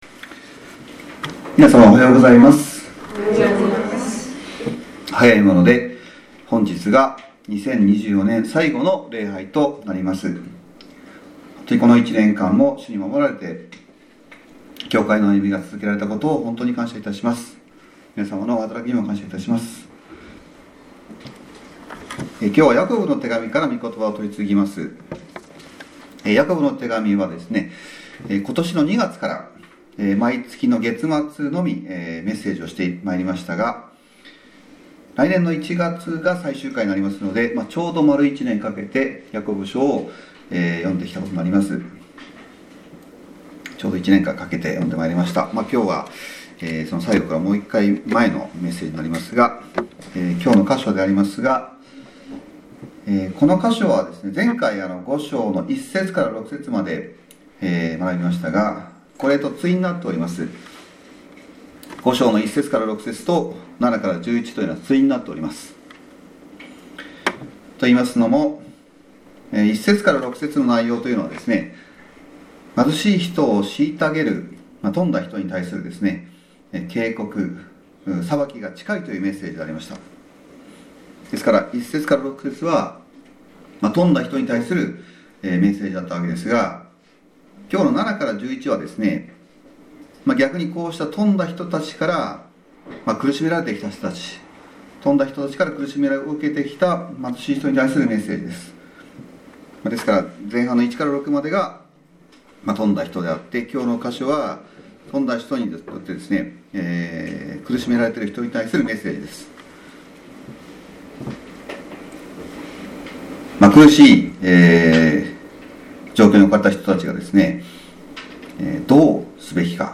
早いもので、本日が2024年の最後の主日礼拝になります。